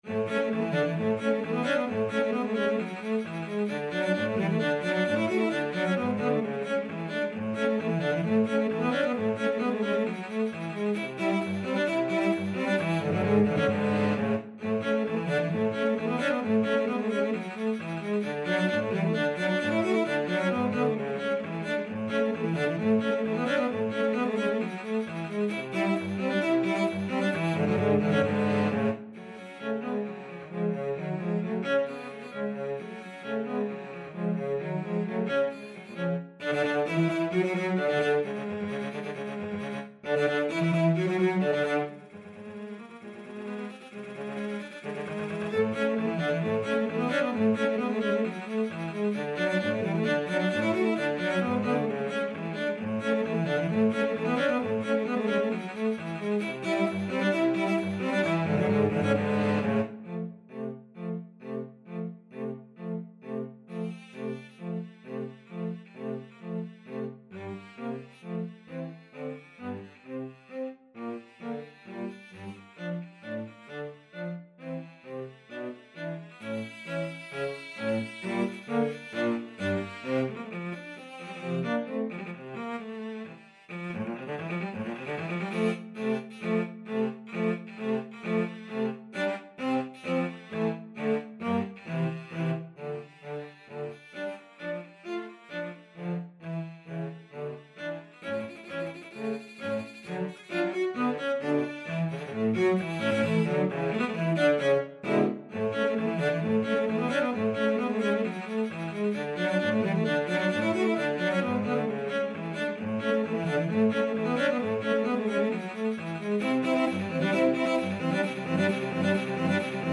Cello 1Cello 2Cello 3
2/4 (View more 2/4 Music)
Cello Trio  (View more Intermediate Cello Trio Music)
Classical (View more Classical Cello Trio Music)